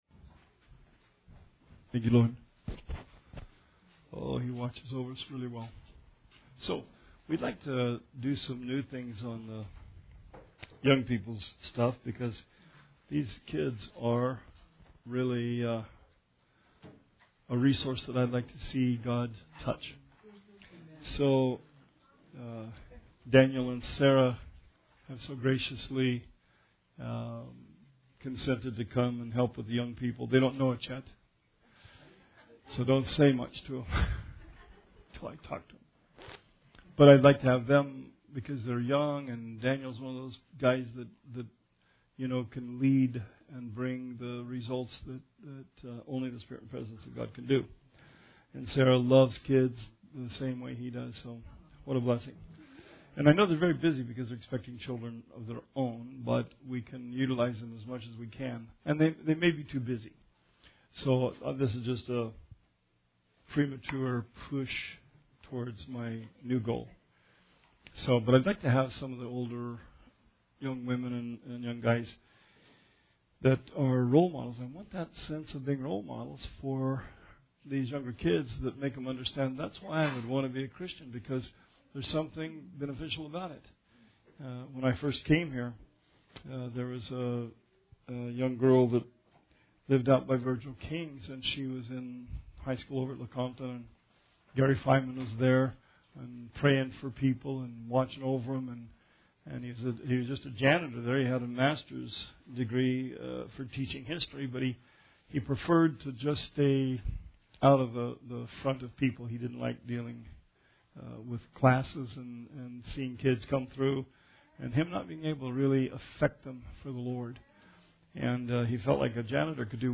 Bible Study 4/4/18